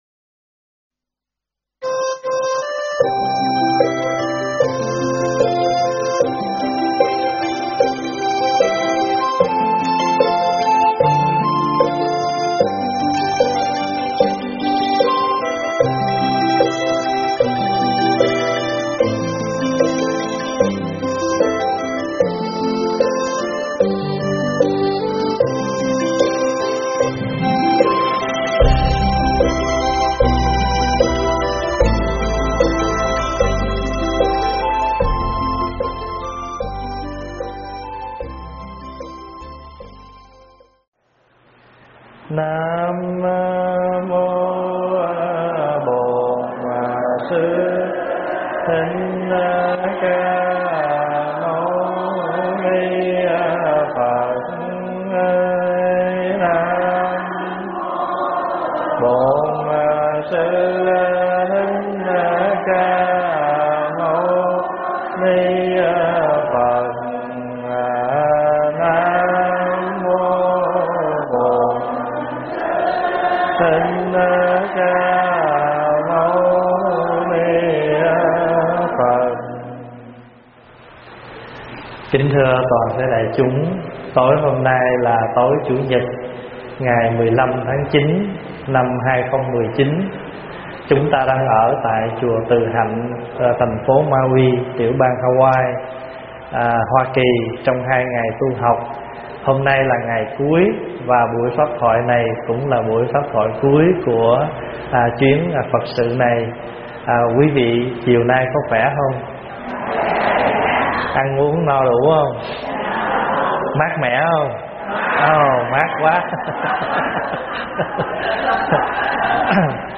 Thuyết pháp Sáu Điều Chướng
giảng tại Chùa Từ Hạnh Mauii, Hawaii